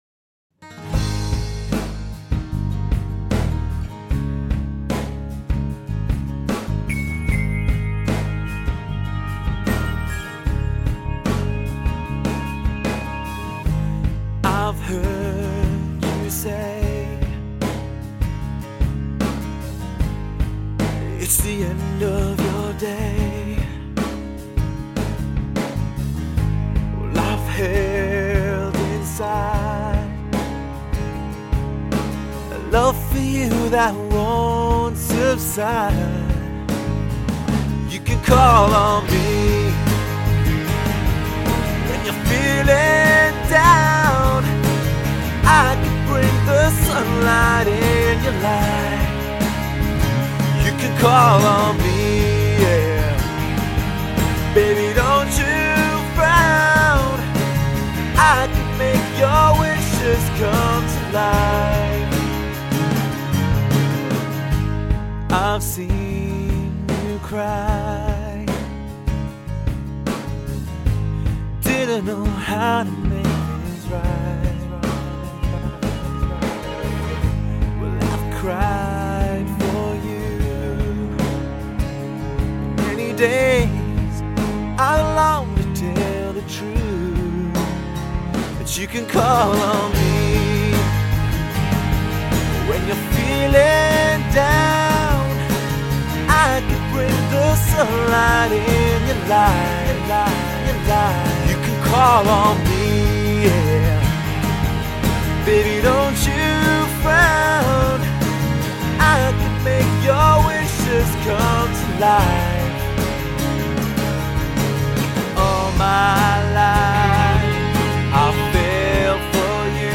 تنظیم، اَرِنجمِنت یا آرانژمان - Arrangement
آیا آهنگی که در مرحله‌ی تنظیم شنیدید ناواضح و ناخوشایند نبود؟!